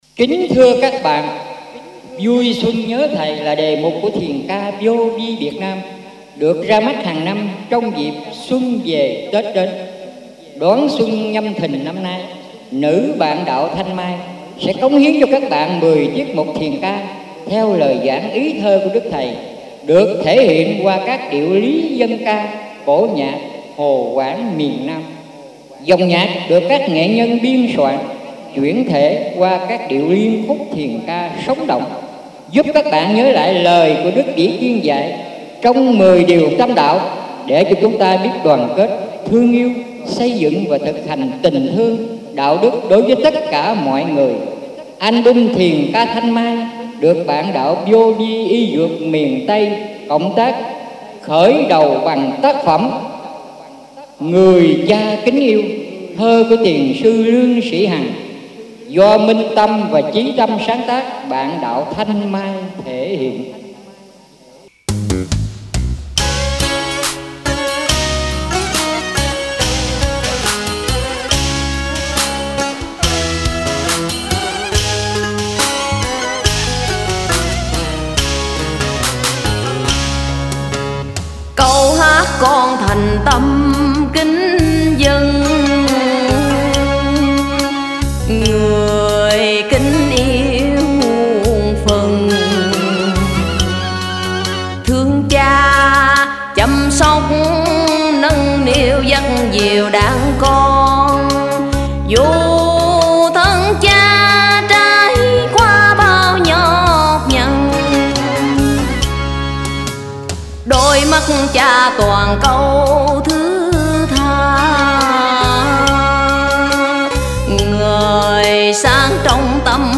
Dân Ca & Cải Lương